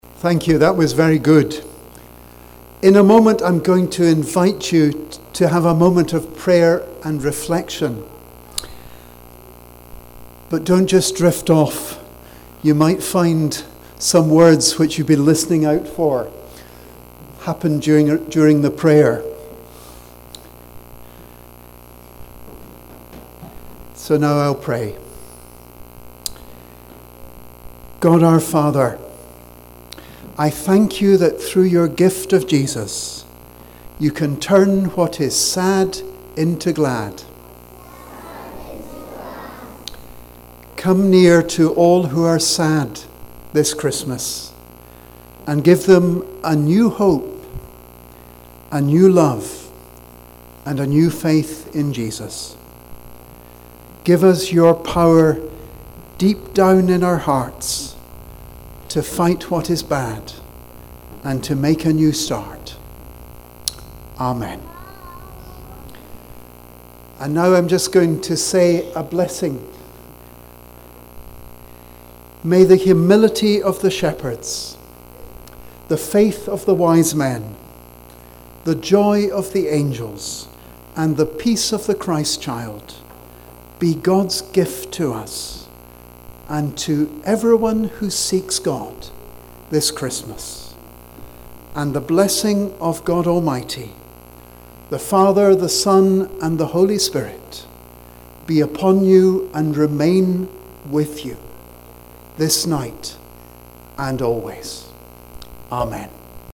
On Wednesday 5th of December St. Mungo's played host to Penicuik and Midlothian Girlguiding, Rangers, Brownies, Trefoil and Rainbows girls, parents and friends, for their annual Christmas Service.
prayer, reflection and blessing' with us all.